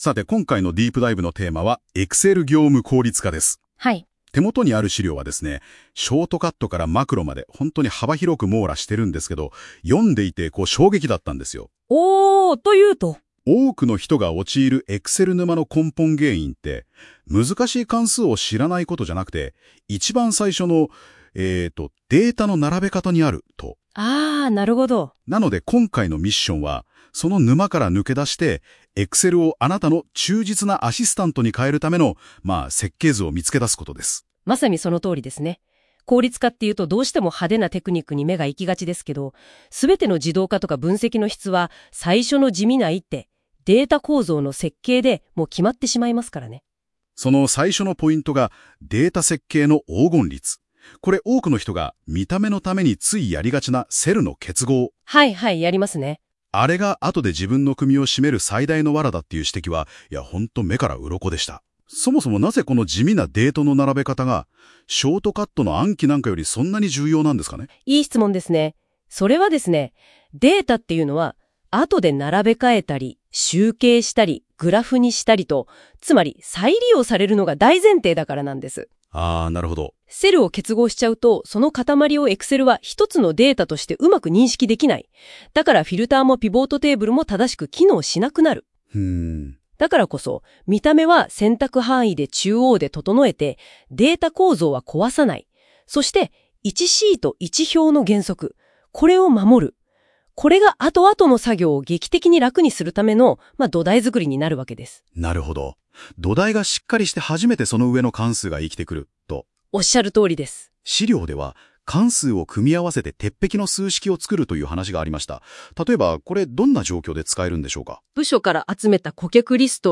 【音声解説】Excel効率化の罠：関数より重要な「データ構造の黄金律」とVBAに頼らない自動化への道筋
音声解説を追加しました。